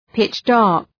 Προφορά
{,pıtʃ’dɑ:rk} (Επίθετο) ● θεοσκότεινος